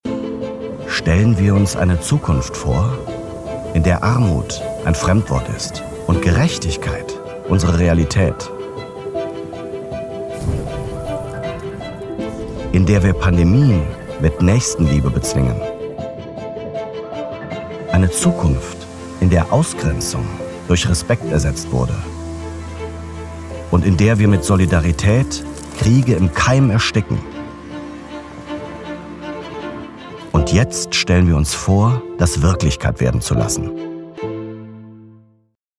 Russisch Imagefilm